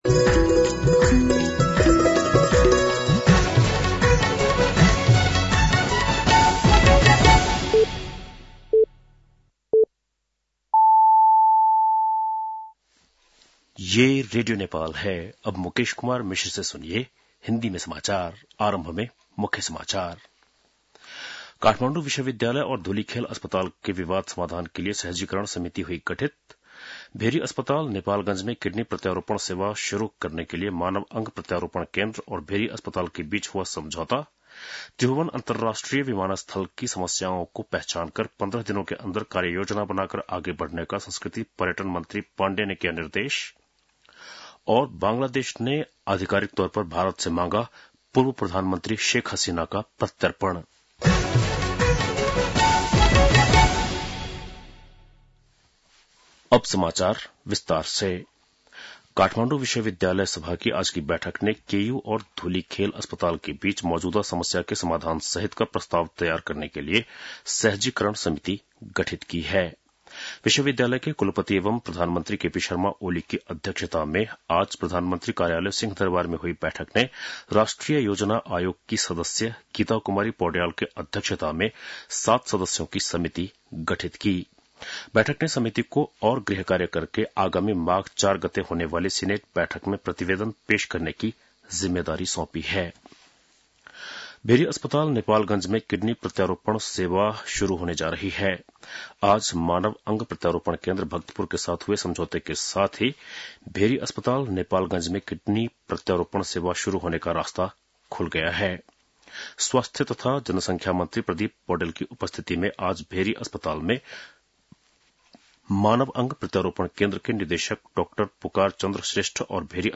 बेलुकी १० बजेको हिन्दी समाचार : ९ पुष , २०८१